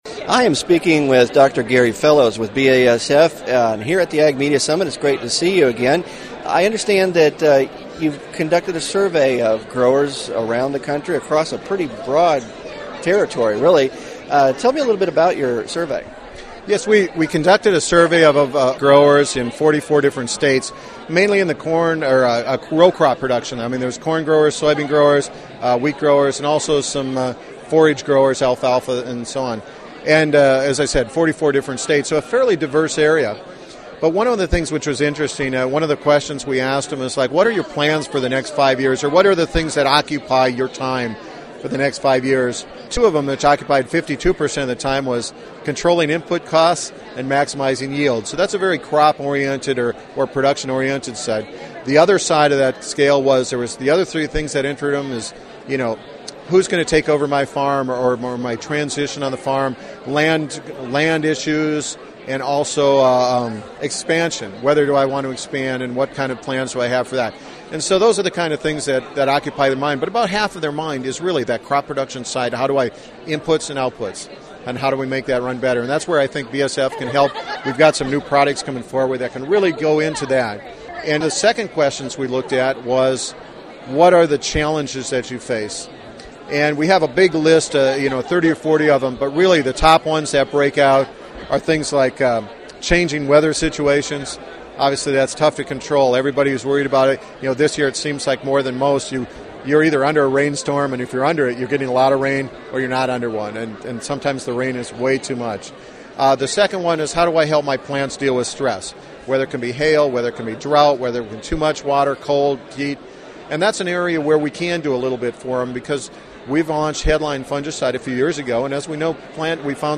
Interview
Ag Media Summit, Audio, BASF